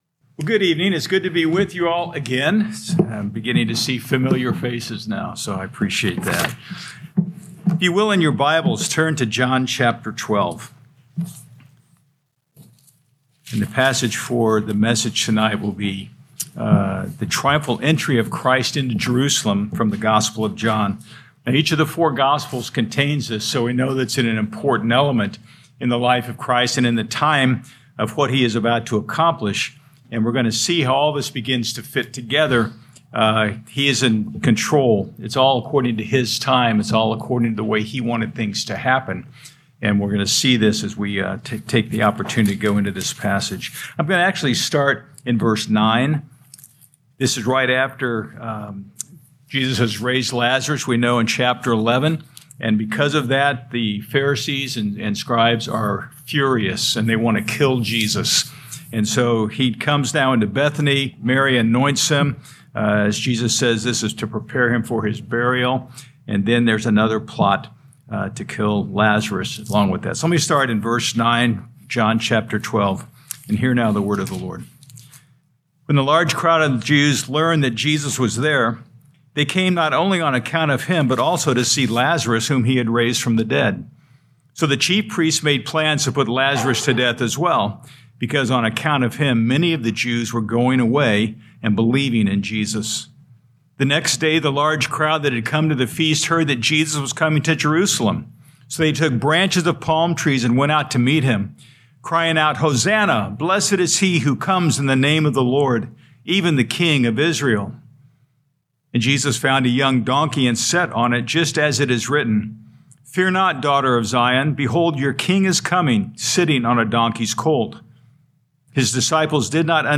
2026 John Evening Service Download